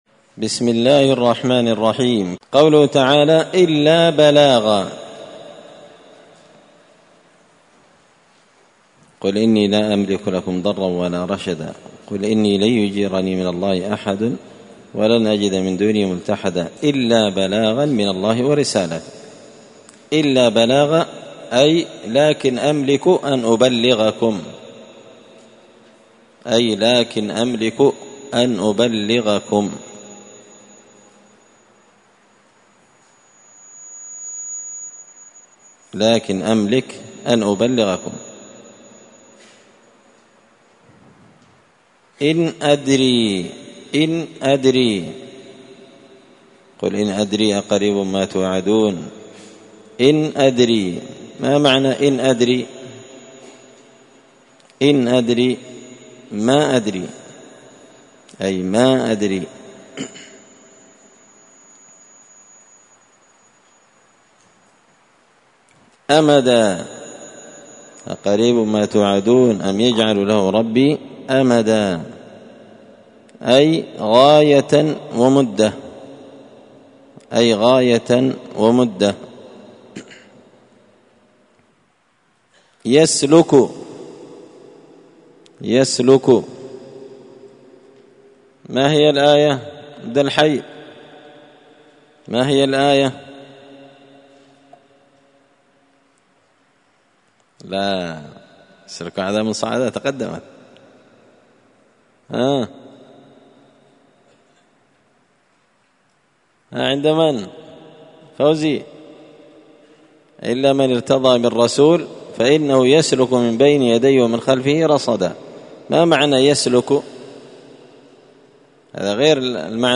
زبدة الأقوال في غريب كلام المتعال الدرس الثامن والثمانون (88)